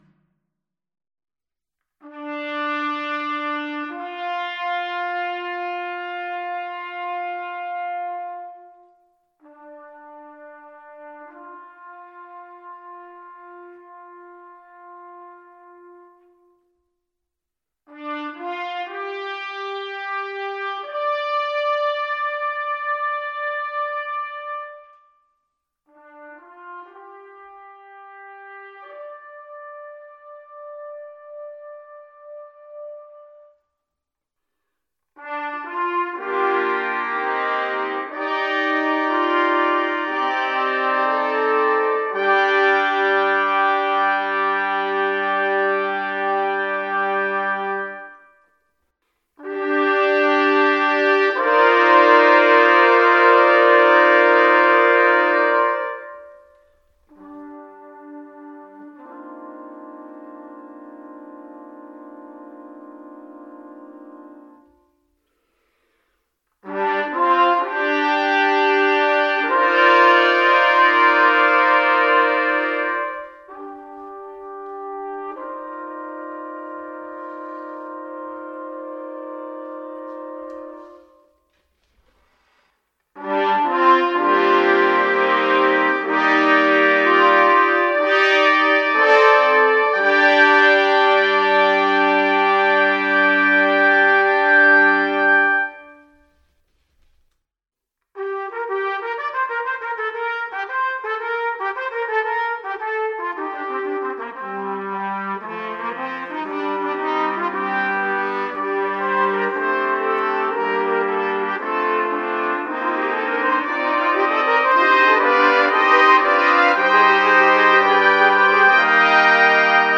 a bold, cinematic piece